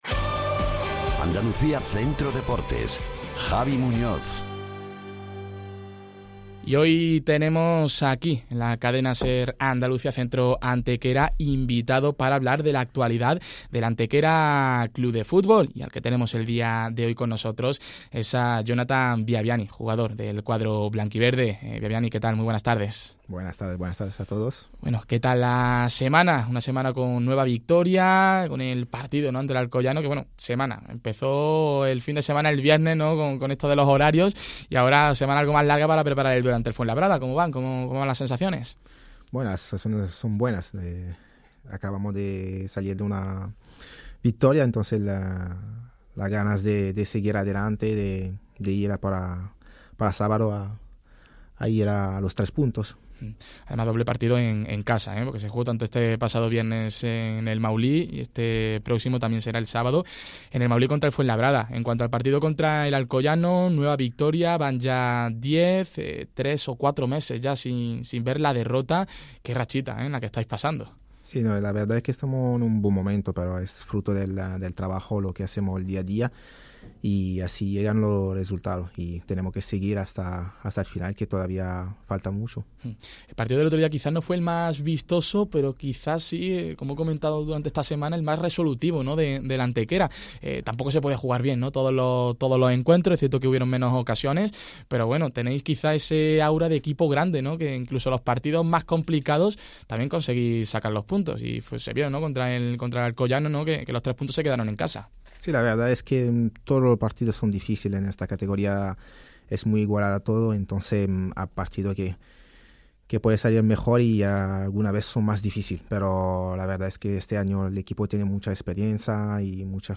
Entrevista Jonathan Biabiany (Antequera CF)